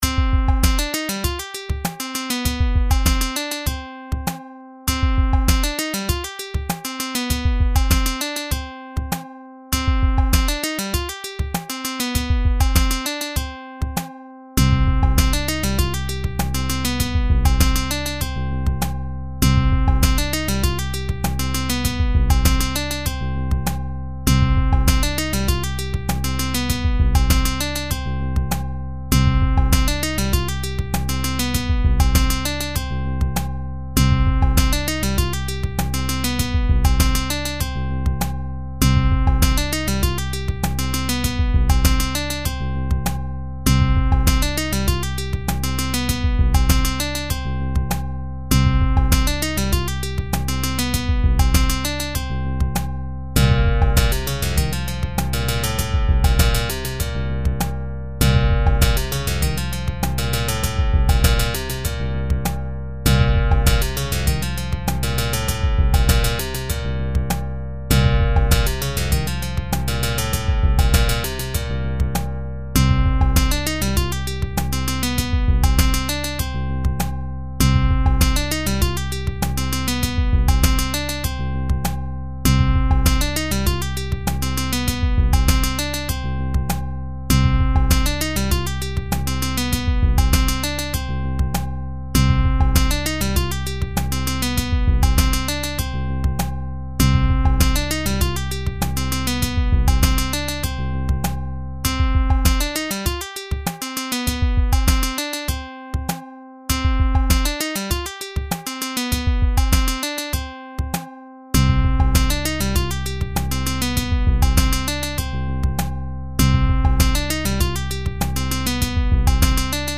低音とパーカッションが幅キカしててたまにはこんなんでも。